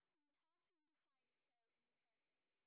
sp19_train_snr10.wav